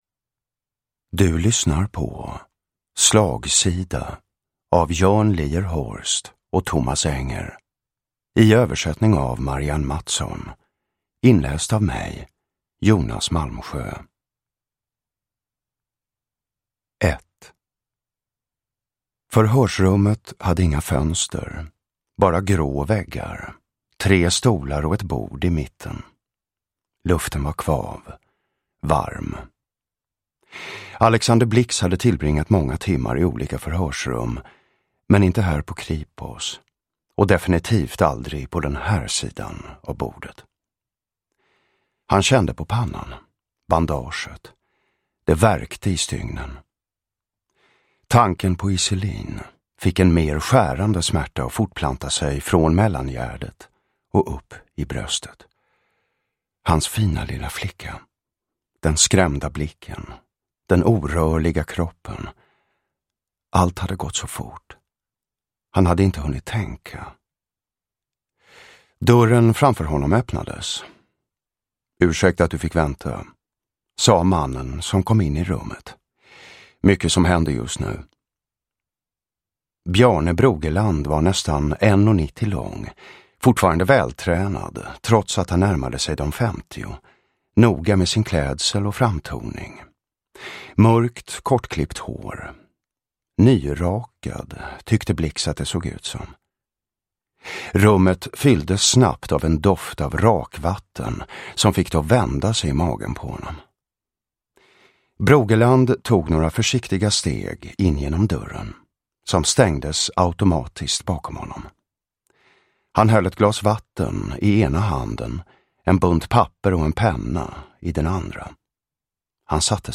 Slagsida – Ljudbok – Laddas ner
Uppläsare: Jonas Malmsjö